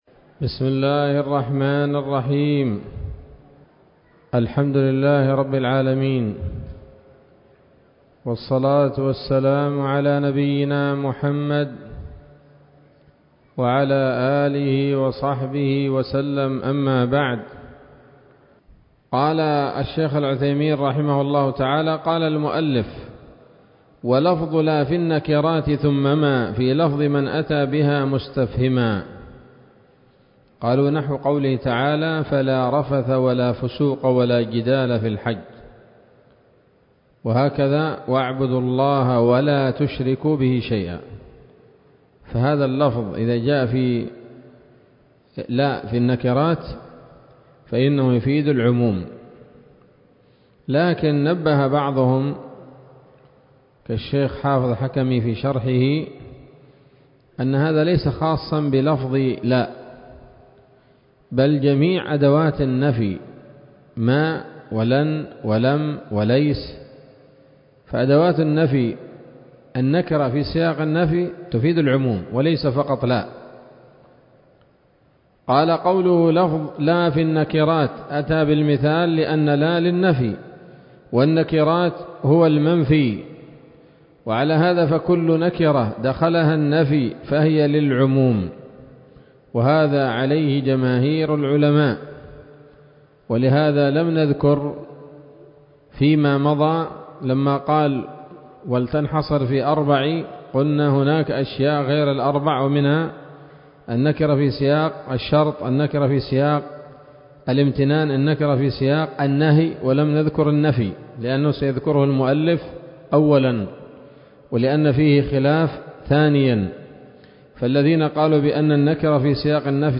الدرس التاسع والثلاثون من شرح نظم الورقات للعلامة العثيمين رحمه الله تعالى